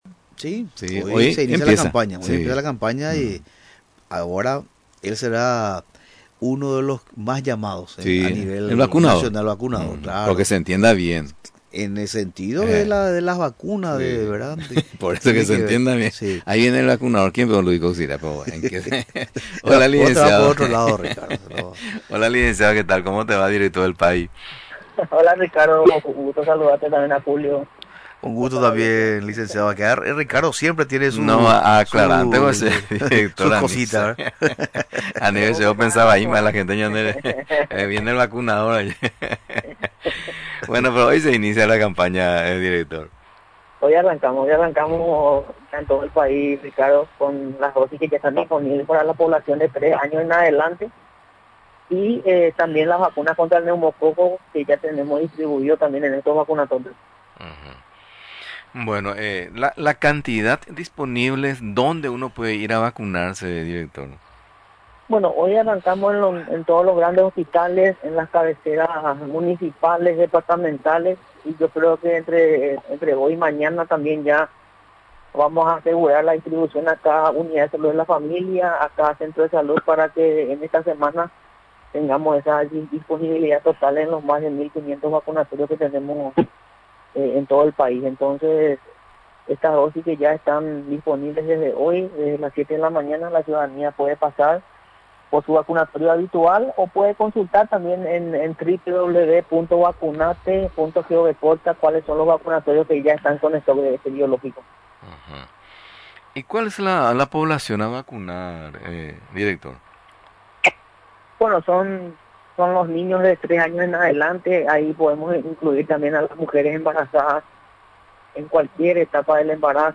Durante la entrevista en Radio Nacional del Paraguay, explicó que la Fase 1, arranca hoy.